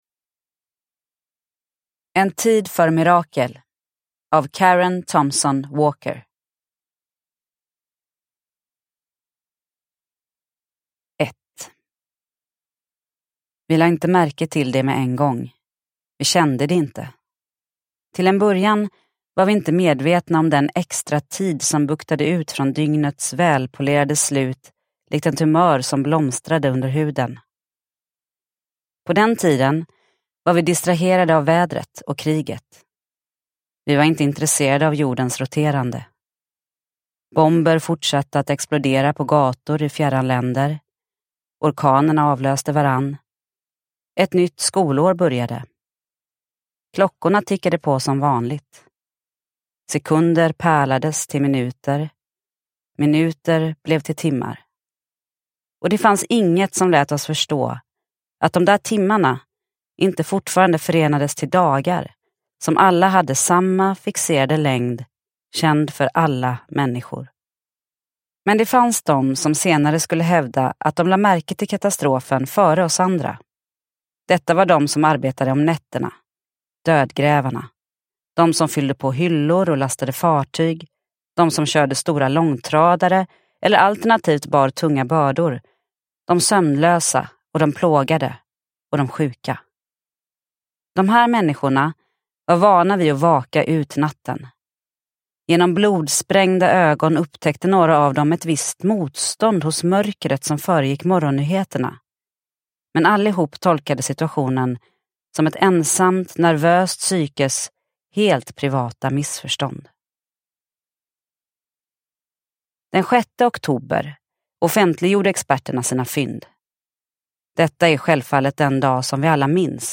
En tid för mirakel – Ljudbok – Laddas ner